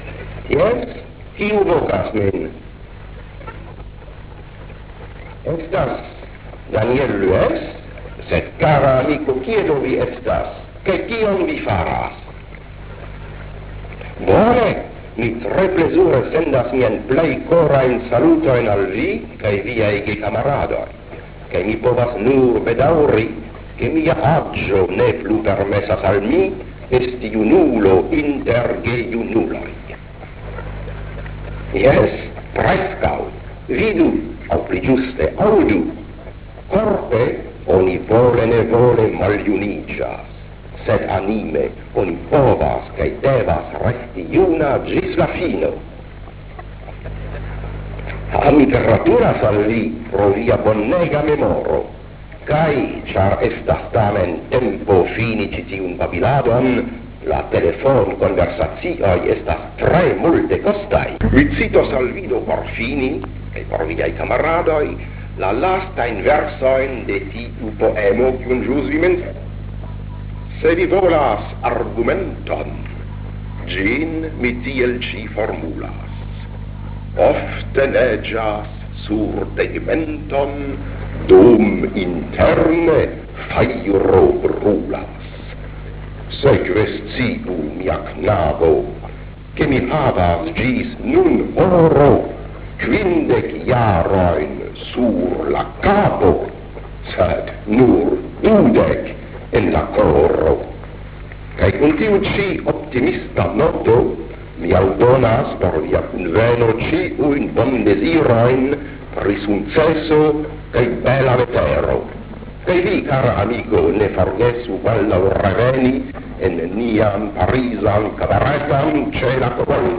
Sonregistrita en 1951 kaj al ni disponigita danke al
Li akceptis mian proponon kaj "registris" sian voĉon sur mian magnetofonon, nur kun liaj respondoj.